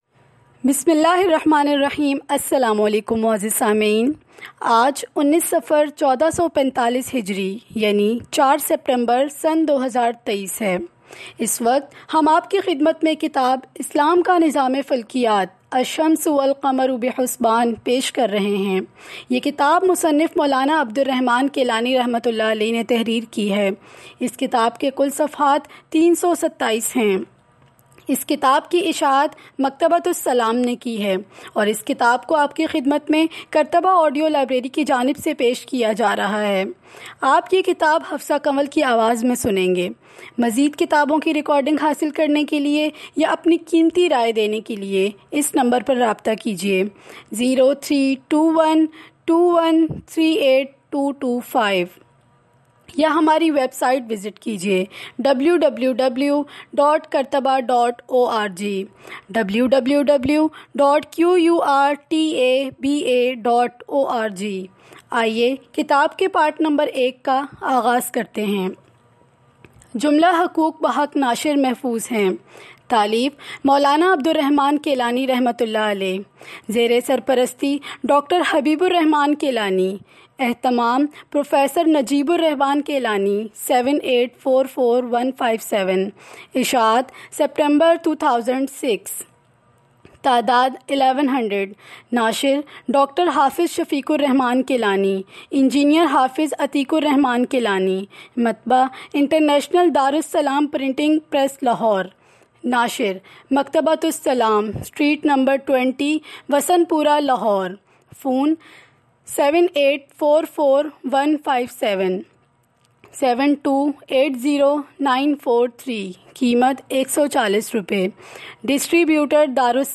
Listen the first part of the audio book below as a demo and if you like the book go ahead and add this audio book into your weekly reading list by pressing the link below the audio player and then go to your reading list and download the audio book Download demo